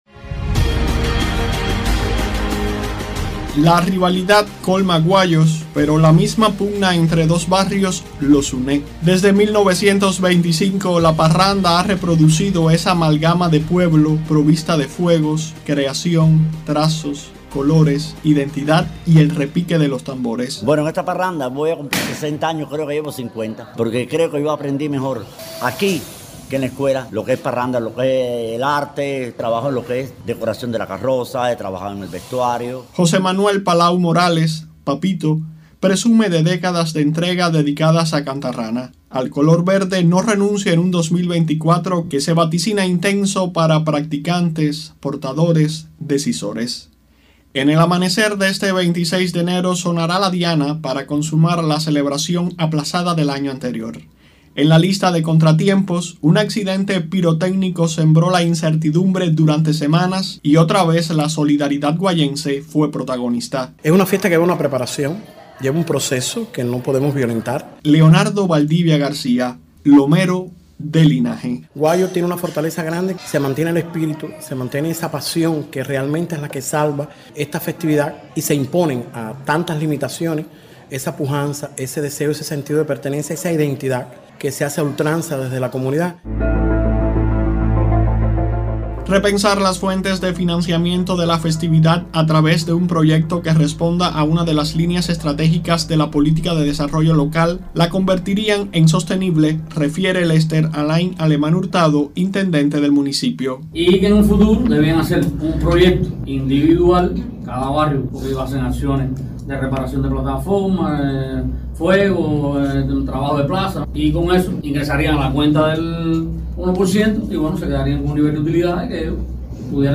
Parranda